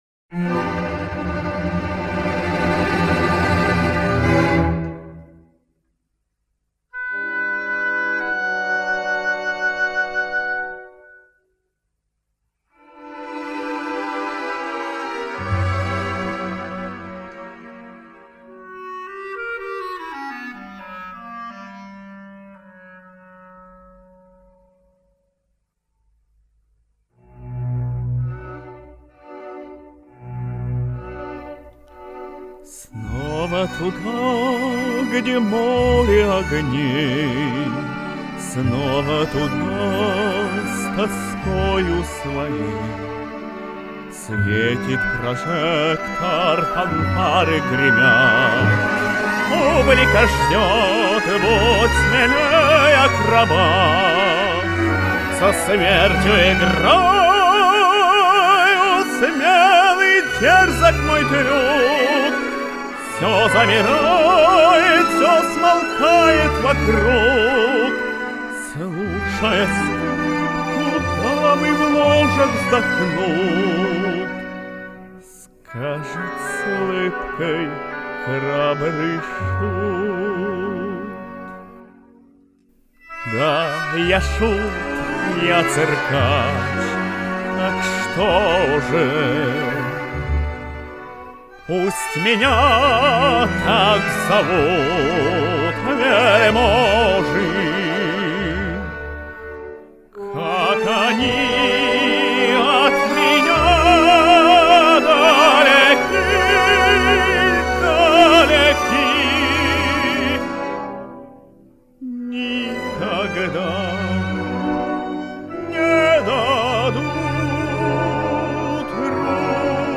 «Классическое произведение»
Какой у Вас красивый голос- чистый, как ручеек